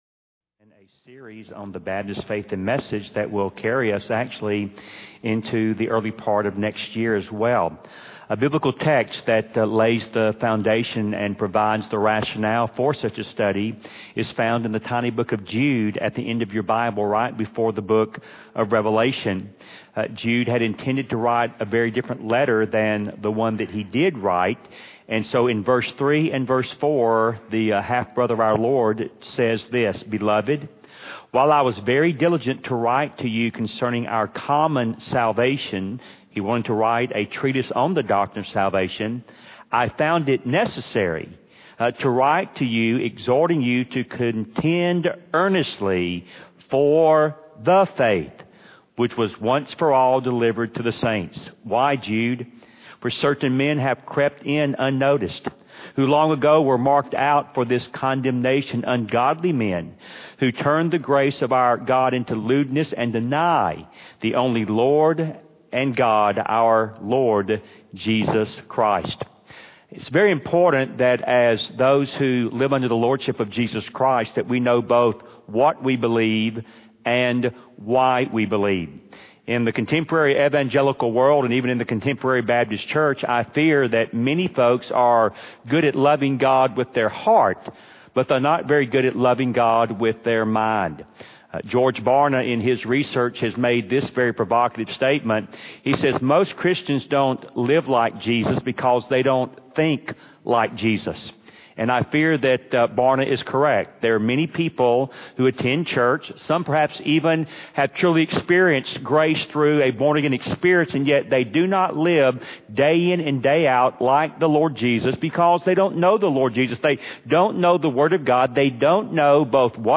at Wake Cross Roads Baptist Church in Raleigh, NC.